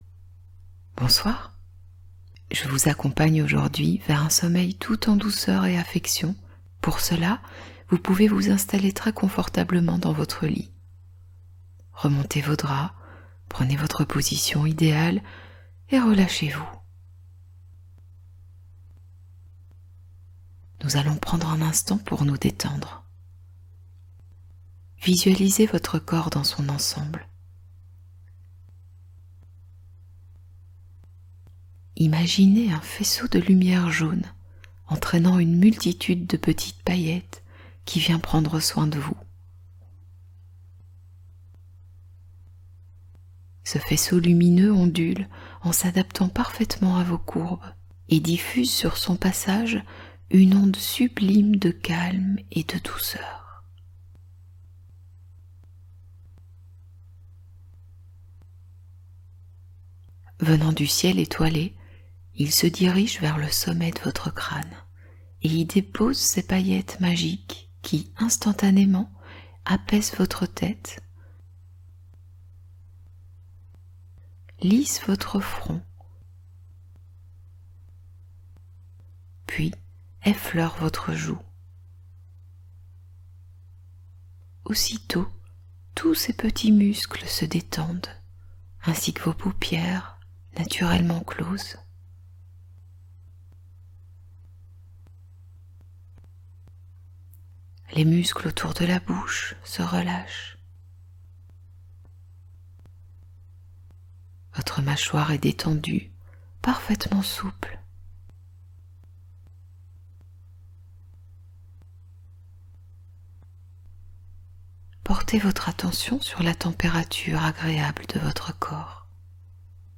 Dormez heureux : Hypnose sommeil qui augmente votre niveau de bonheur ★ Tendresse et douceur